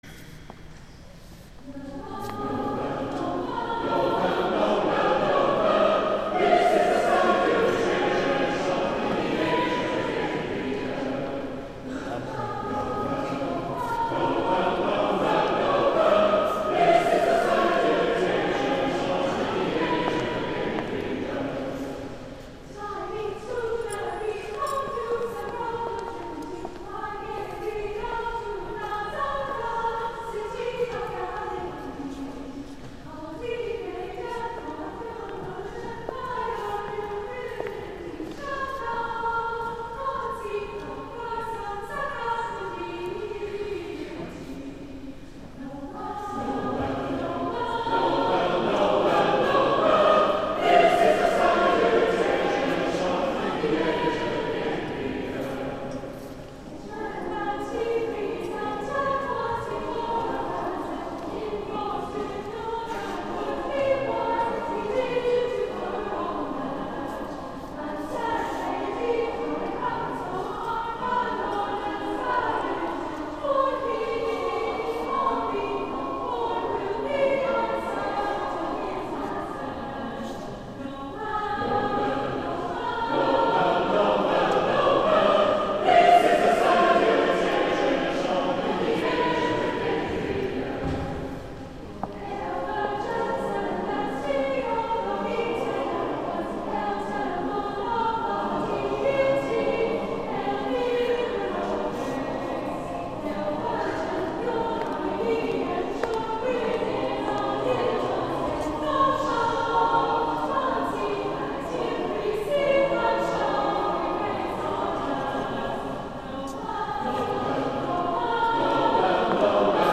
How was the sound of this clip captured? Christmas Eve Holy Eucharist